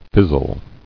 [fiz·zle]